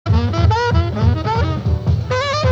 Howard Theater, Washington DC)より